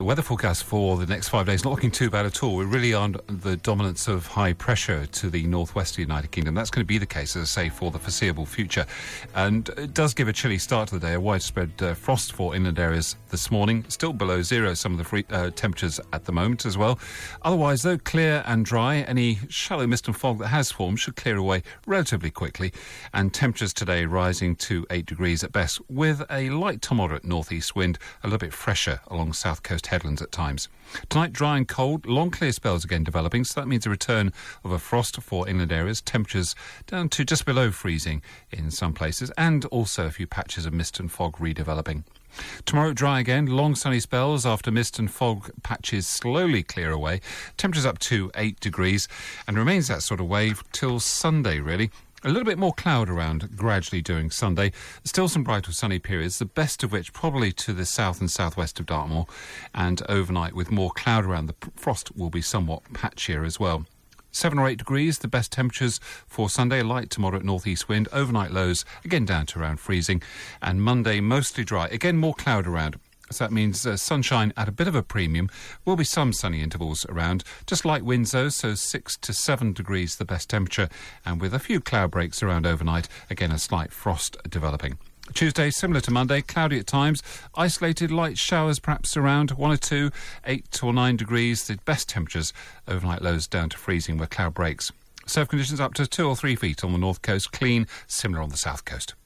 5 day forecast for Devon from 8.35AM on 22 November